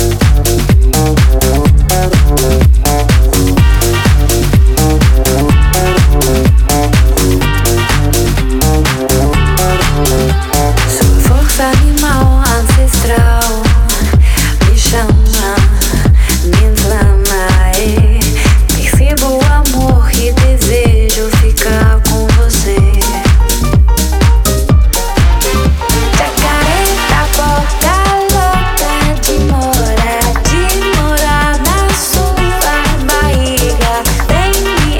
Жанр: Танцевальная музыка
# Dance